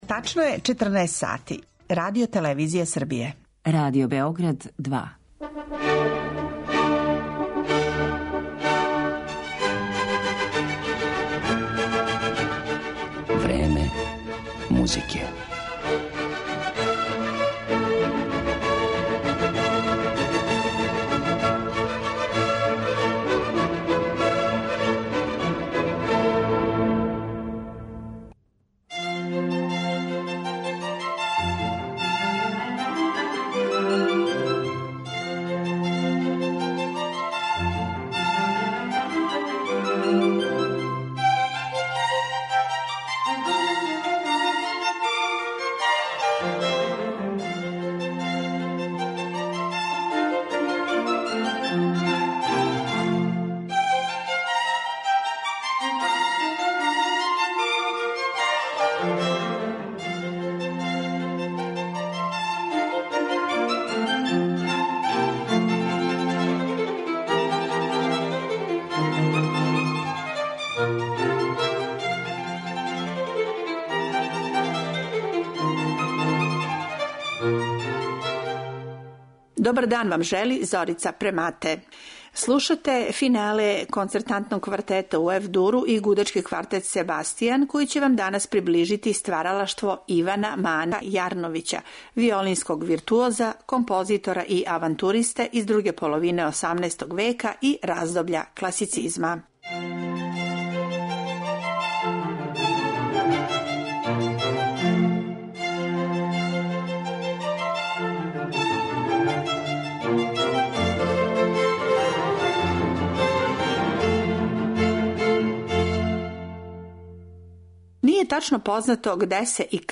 концертне квартете и концерте за виолину.